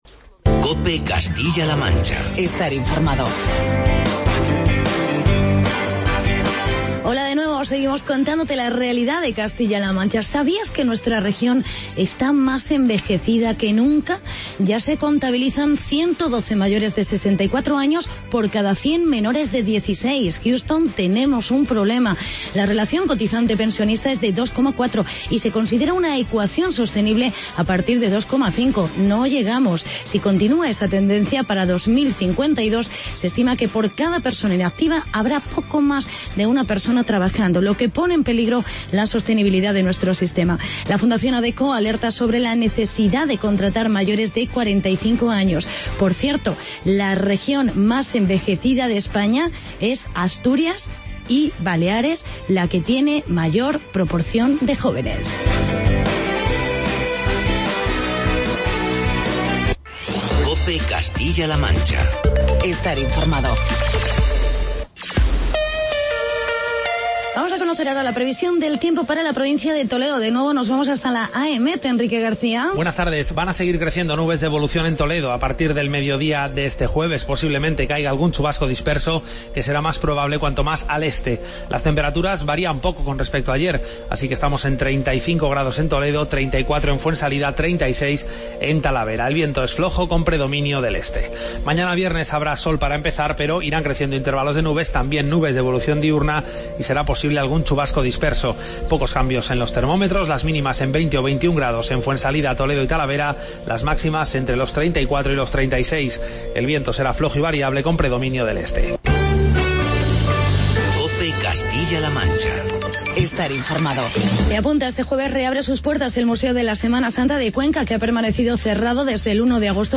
Hablamos del envejecimiento en Castilla La Mancha y entrevista con sindicatos sobre la situación de los conserjes en el Museo del Ejército.